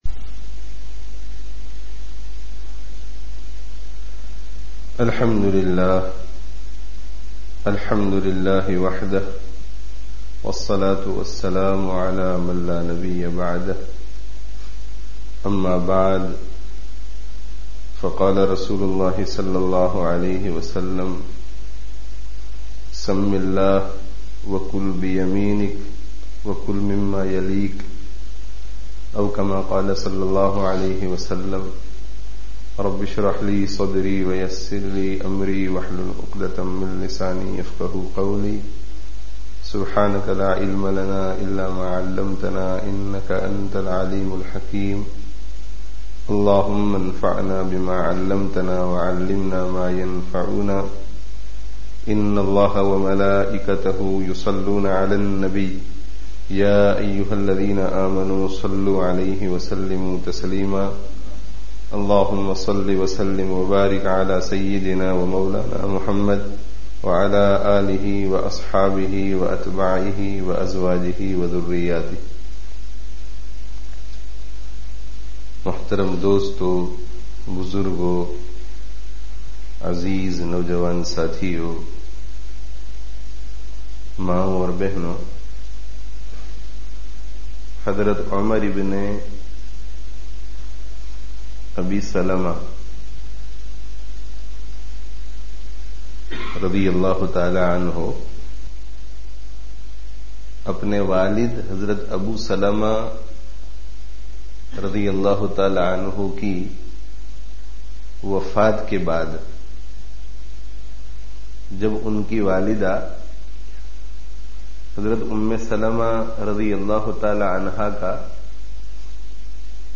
Bachcho(n) se Awr Yatīmo(n) se Muhabbat Karo [Jumu'ah Bayan] (Masjid an Noor, Leicester 21/03/09)